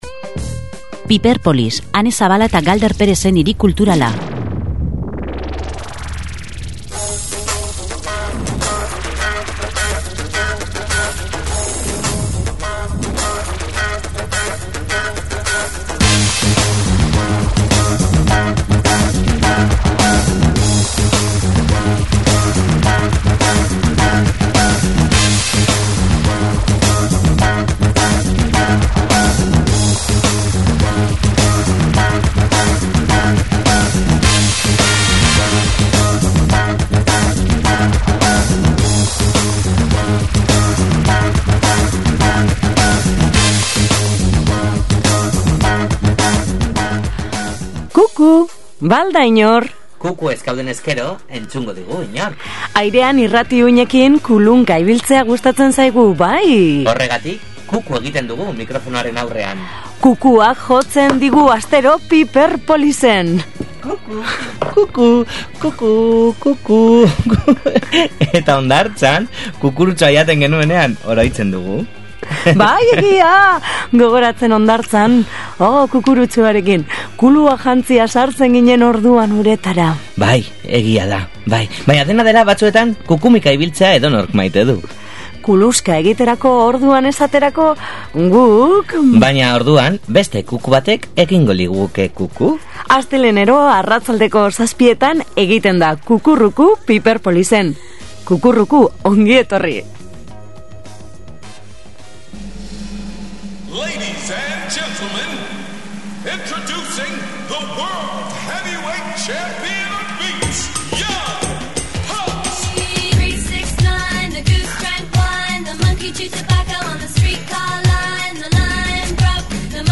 Taldekiden musika entzun digu ‘ Cirijanos’, gainera gurean inpreobizatu egin du zuzenean.